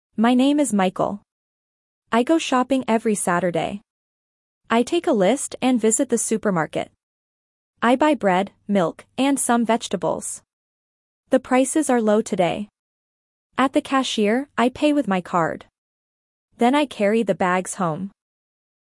Dictation A2 - Shopping Experience